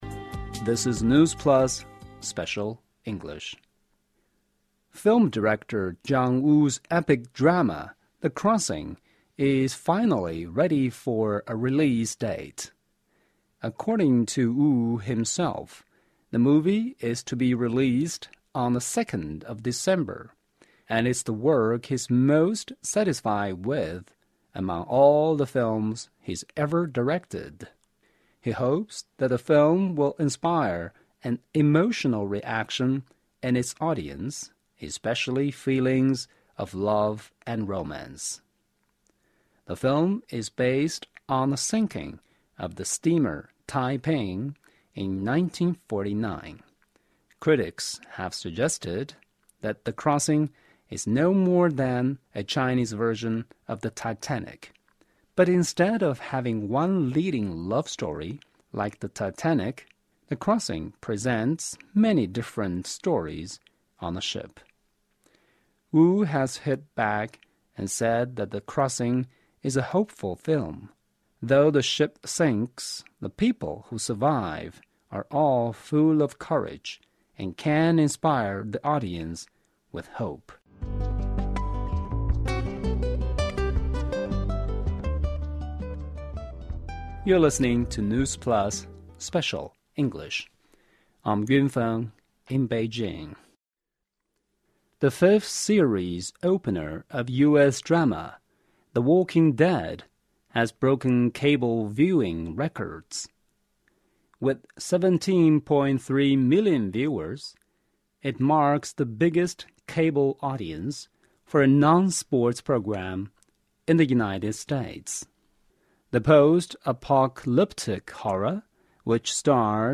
News Plus慢速英语:吴宇森称太平轮是一生最满意之作 行尸走肉第五季首播收视破纪录